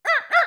doglittle2.wav